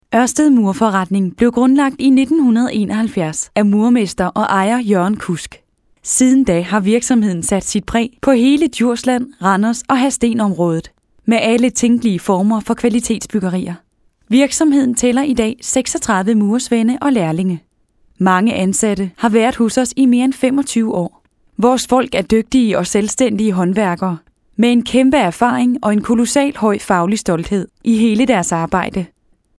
Sprecherin dänisch
Sprechprobe: Industrie (Muttersprache):
Professionell danish female voice over artist